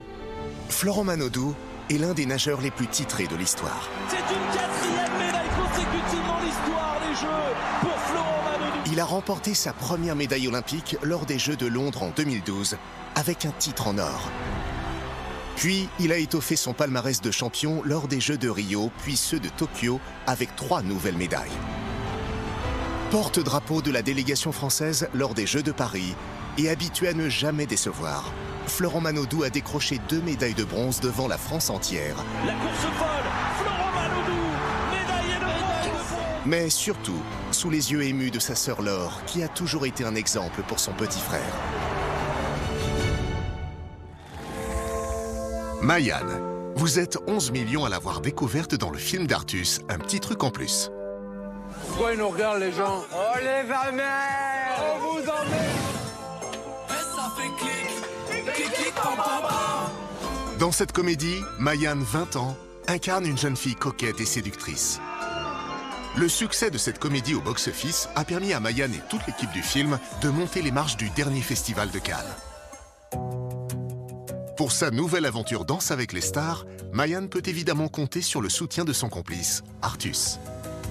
Voix off homme des candidats - Danse avec les stars
Classe et valorisant.
Monté, enregistré et mixé chez Atlantis TV.
Voix off homme des magnetos de présentation de candidats.